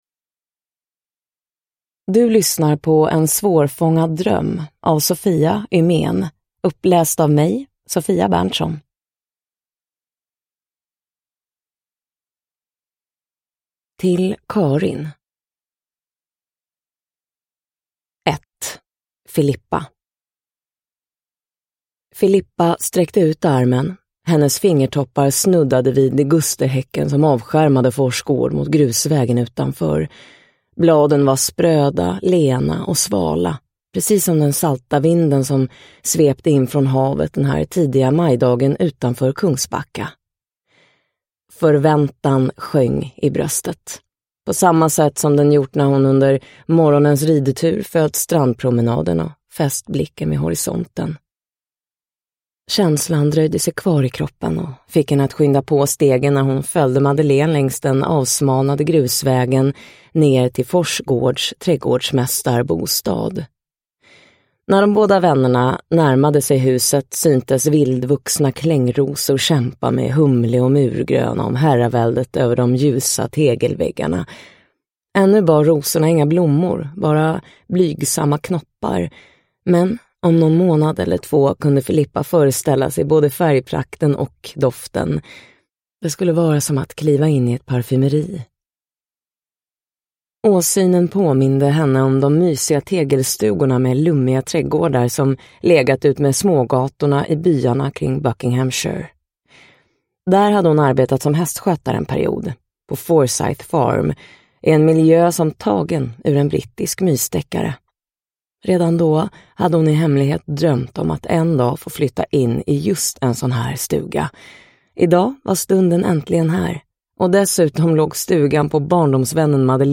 En svårfångad dröm – Ljudbok